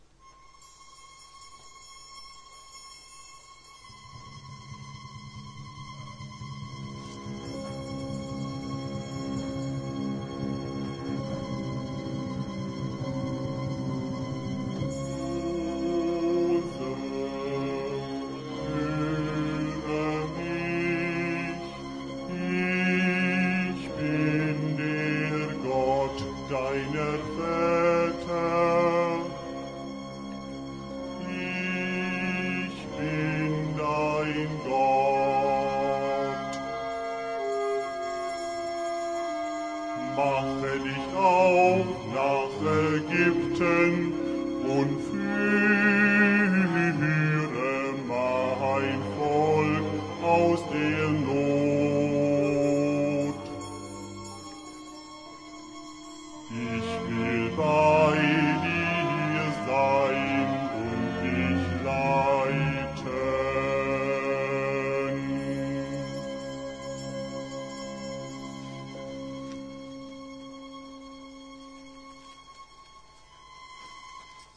Ariosa (Gottes Stimme)
03-ariosa_gottes_stimme.mp3